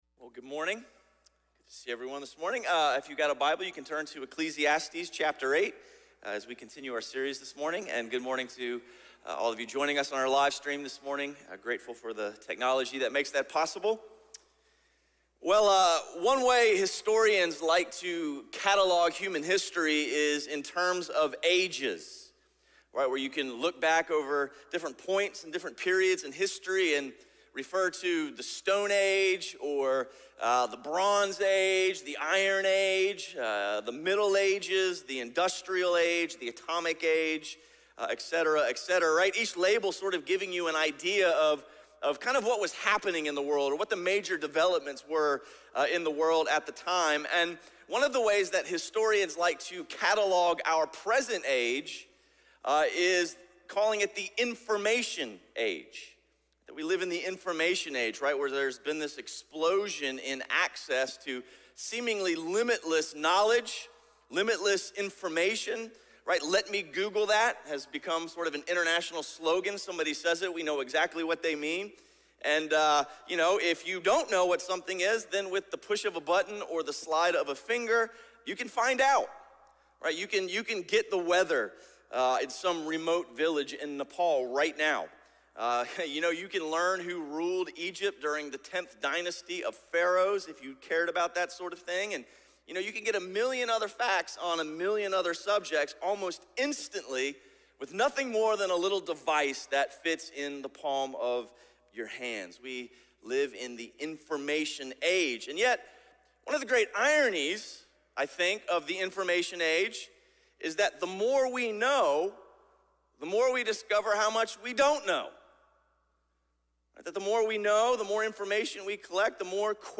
Sermon series through the book of Ecclesiastes.